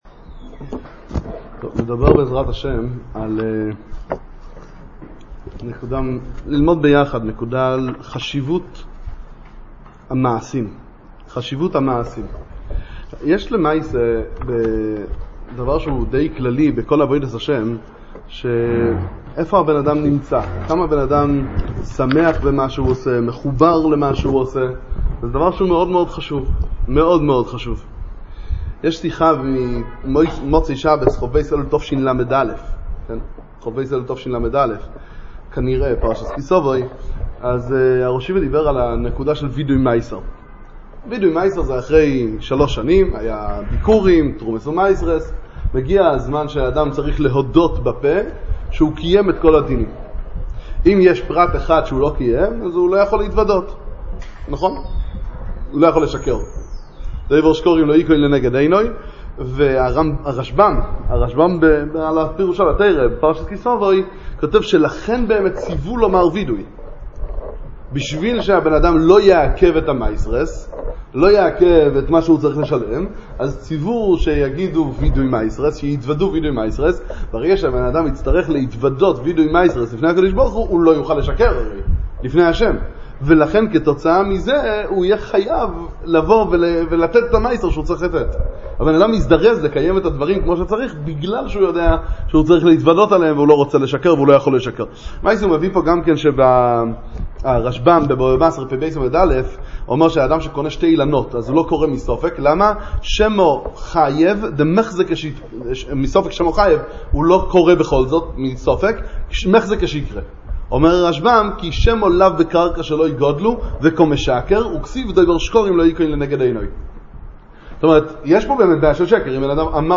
שיעורי תורה